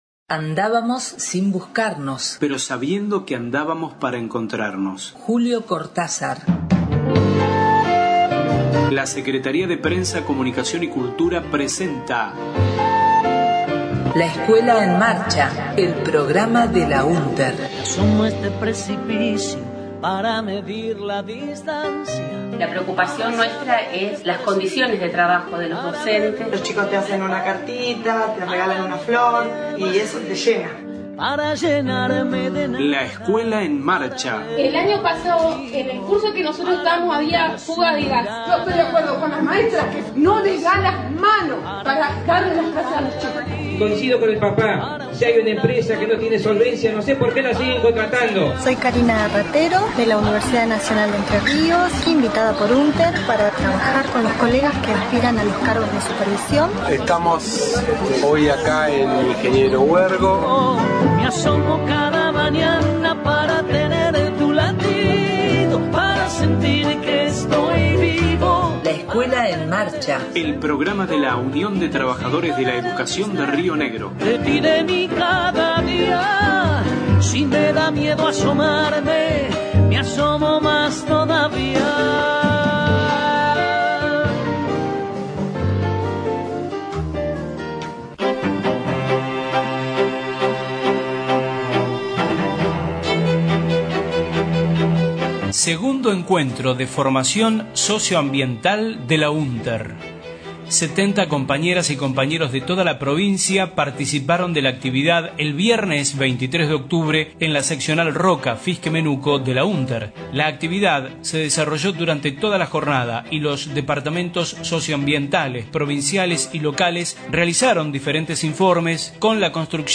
Audios del Segundo Encuentro Socioambiental de la UnTER que se desarrolló el 23/10/15, en la seccional Roca – Fiske Menuco, organizado por el Departamento Socioambiental “Chico Mendes”.